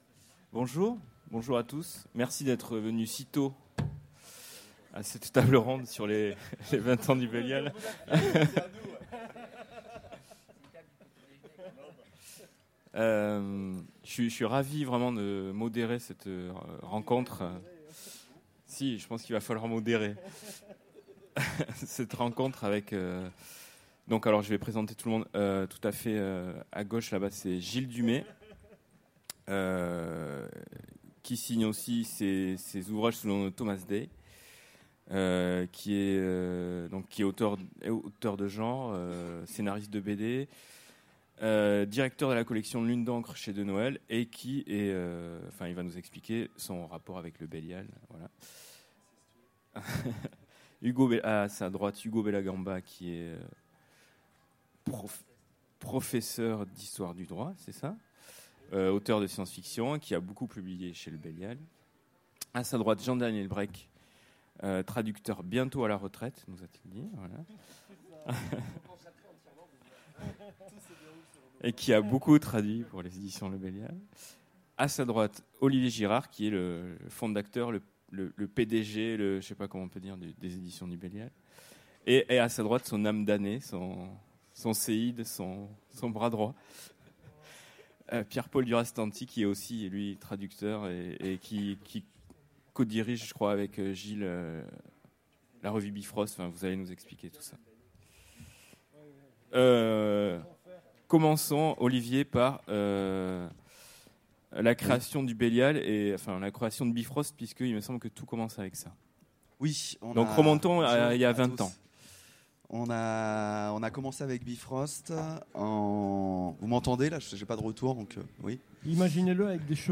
Utopiales 2016 : Conférence Les 20 ans du Belial’
Conférence
Rencontre avec une maison d'édition